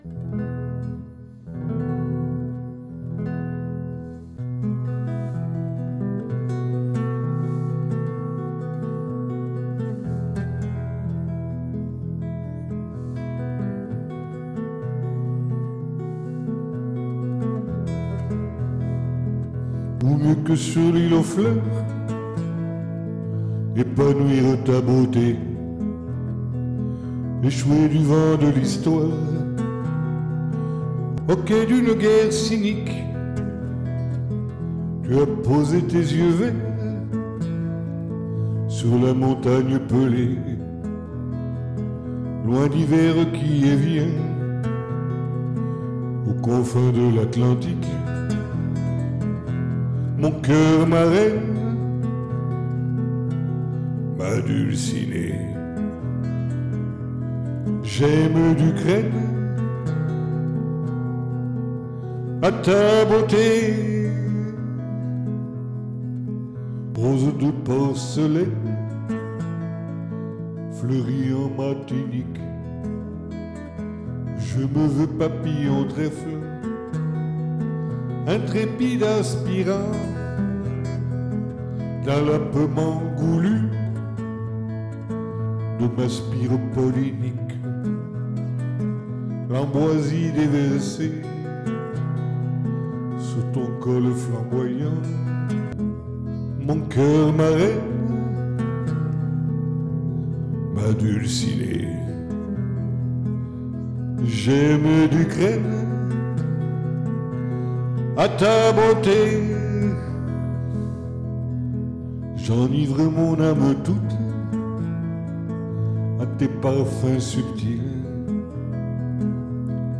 Glamour